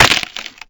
NeckSnap1.ogg